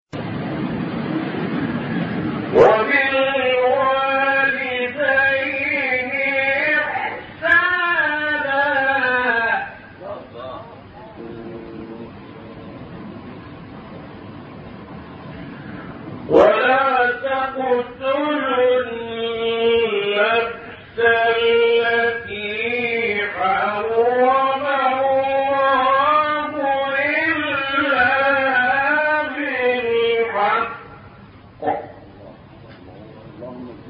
6 فراز صوتی در مقام «کُرد»
گروه شبکه اجتماعی: فرازهایی صوتی از تلاوت شش قاری برجسته مصری که در مقام کُرد اجرا شده‌اند، ارائه می‌شود.